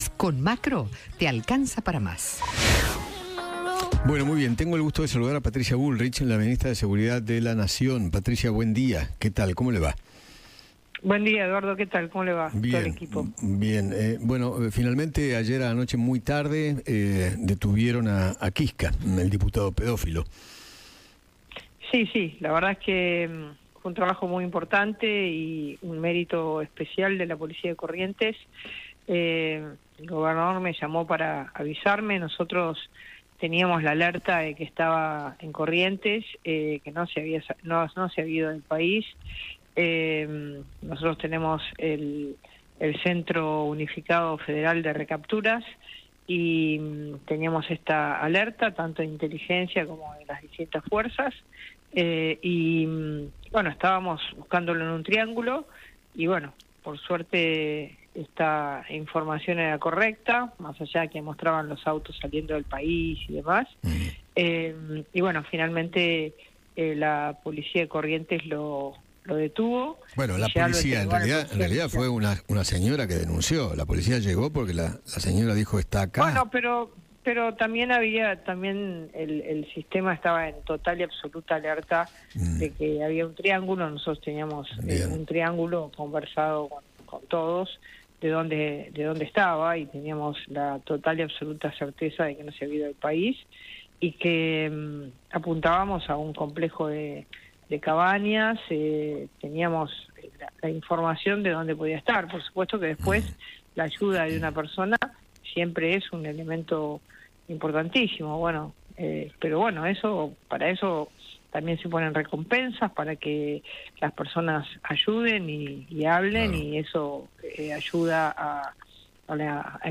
Patricia Bullrich, ministra de Seguridad, habló con Eduardo Feinmann sobre la visita de los legisladores a los genocidas en Ezeiza y se desvinculó completamente de la cuestión.